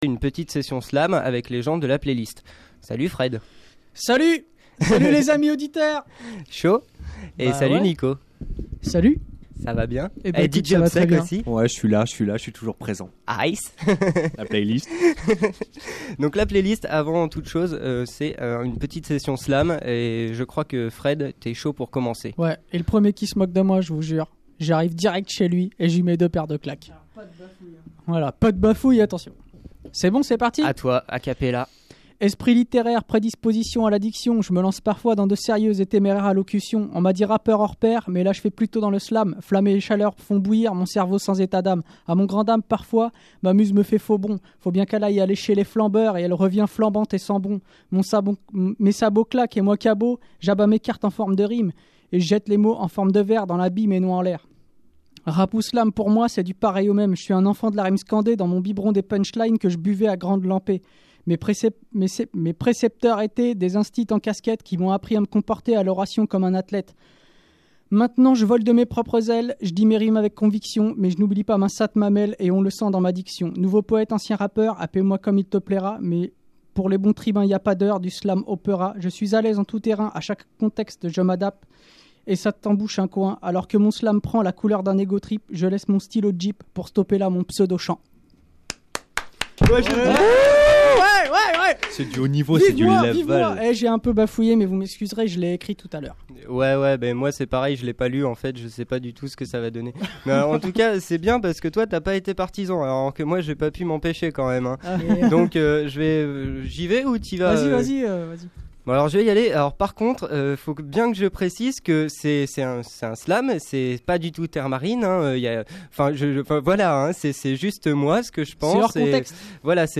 slam pataplaylist.mp3